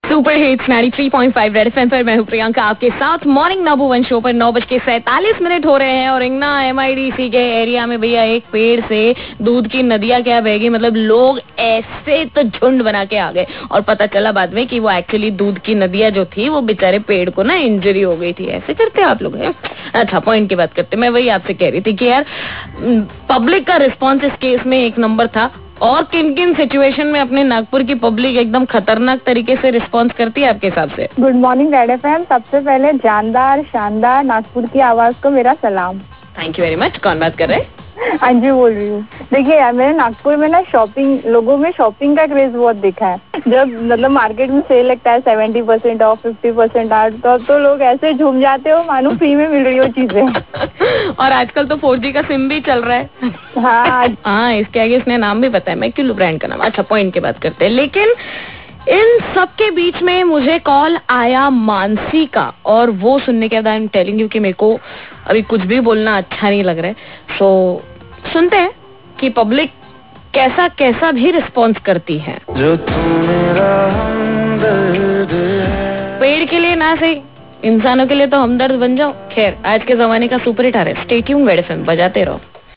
TALKING TO THE CALLERS